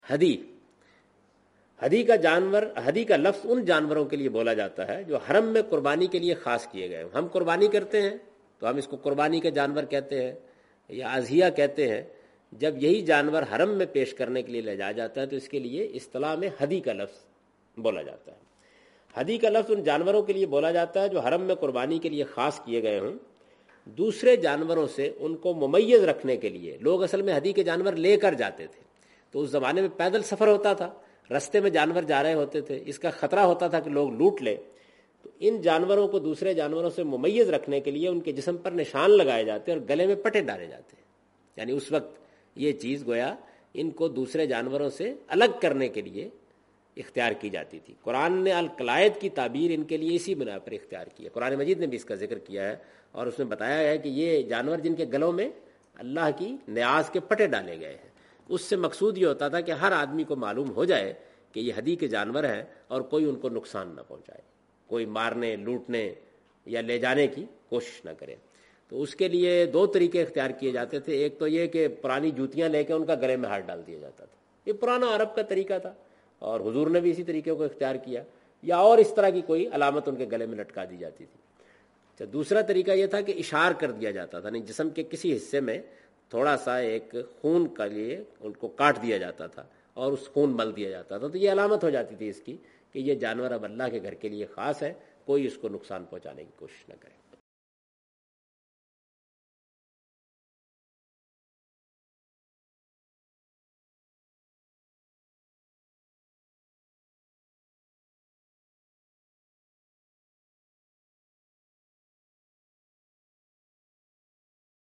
In this video of Hajj and Umrah, Javed Ahmed Ghamdi is talking about "Sacrifice Animals in Hajj".
حج و عمرہ کی اس ویڈیو میں جناب جاوید احمد صاحب غامدی "حج میں ہدی کے جانور" سے متعلق گفتگو کر رہے ہیں۔